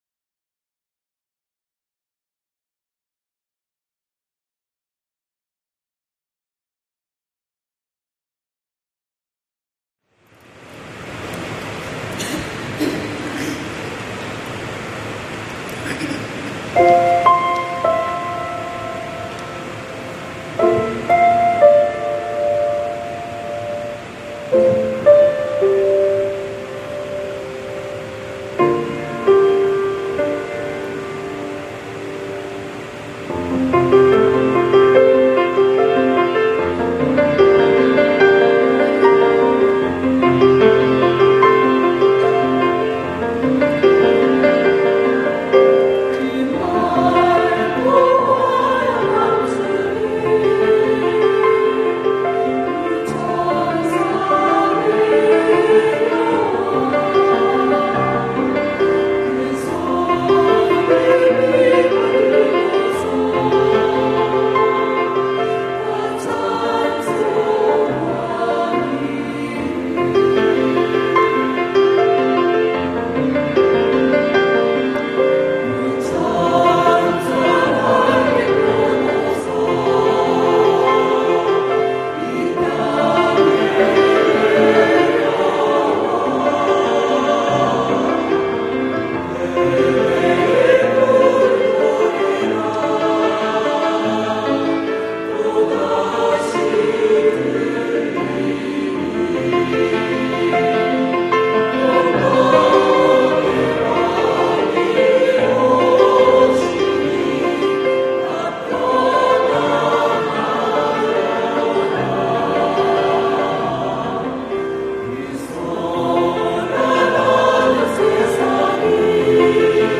그 맑고 환한 밤중에 > 찬양영상